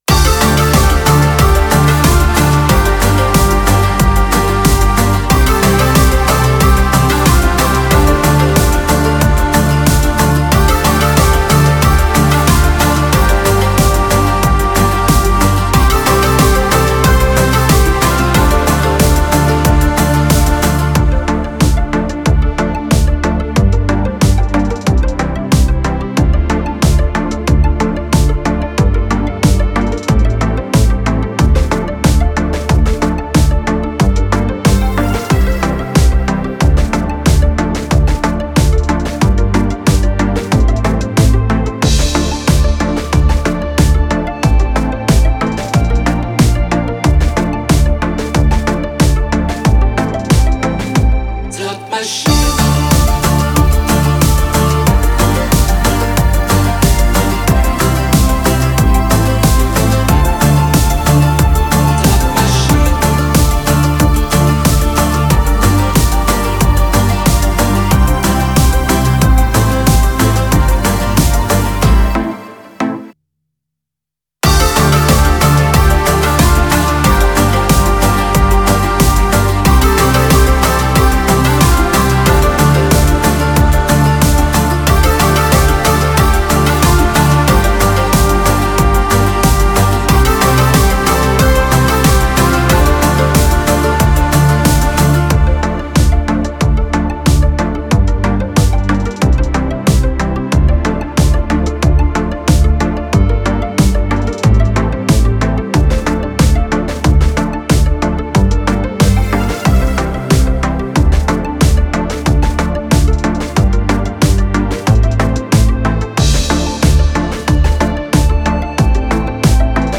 con 15 canzoni tutte ballabili